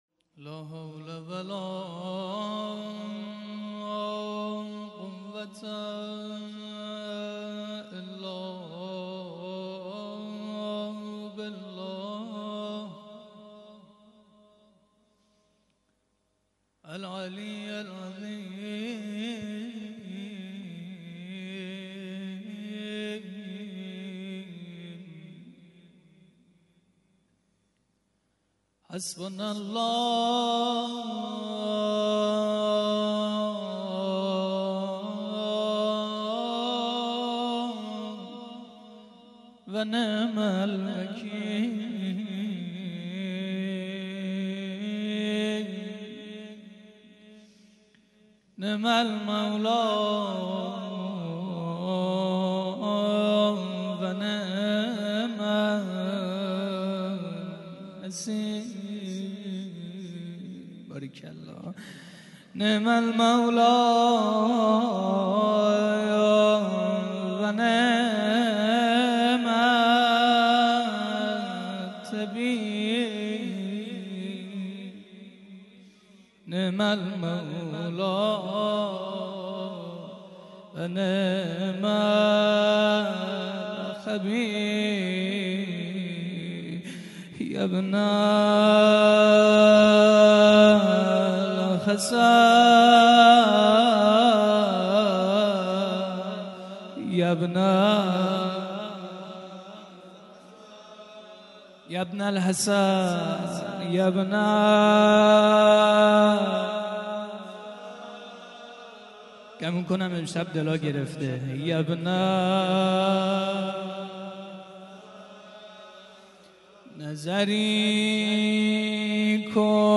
روضه شام شهادت حضرت حمزه سید الشهدا 01.mp3
روضه-شام-شهادت-حضرت-حمزه-سید-الشهدا-01.mp3